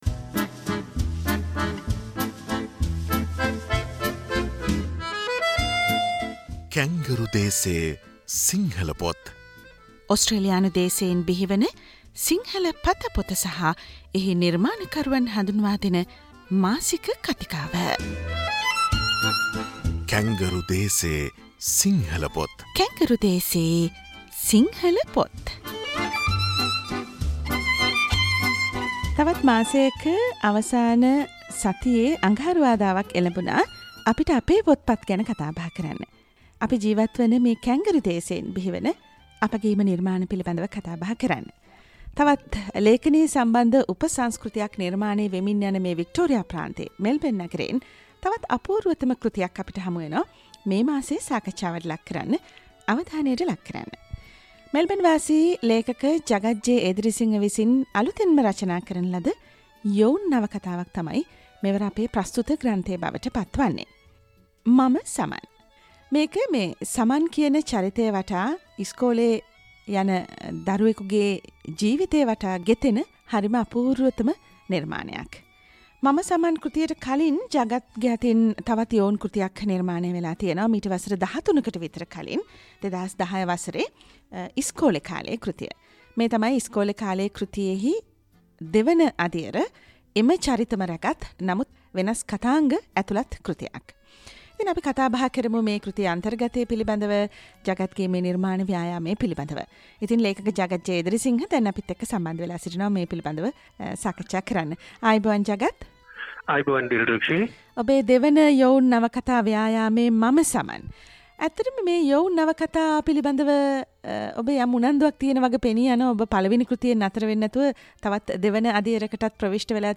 " කැන්ගරු දේසේ සිංහල පොත් " - SBS සිංහල සේවයේ මාසික ග්‍රන්ථ සංකථනය මාසයේ අවසාන අඟහරුවාදා විකාශනය වේ.